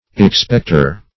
expecter - definition of expecter - synonyms, pronunciation, spelling from Free Dictionary
Expecter \Ex*pect"er\, n.